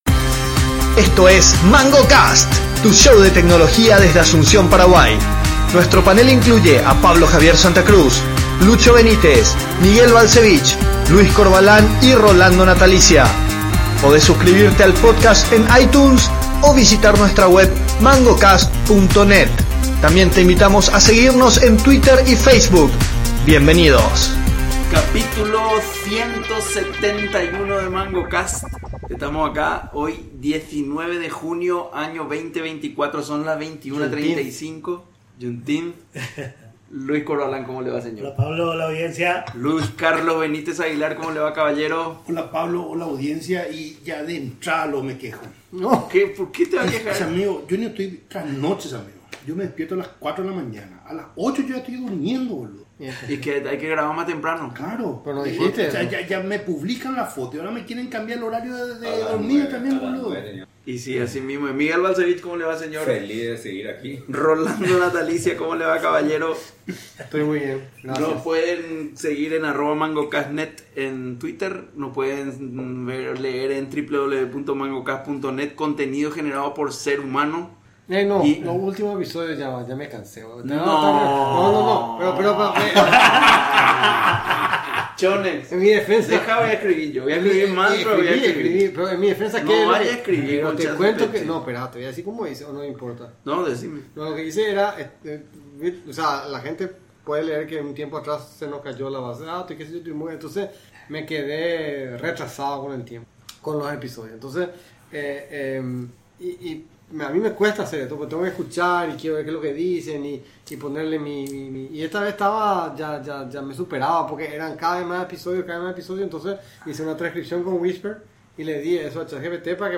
Luego, abordamos las predicciones deportivas teniendo en cuenta los campeonatos de fútbol venideros, mencionando posibles ganadores de la Copa América y la Eurocopa, con opiniones divididas entre los panelistas sobre los posibles campeones.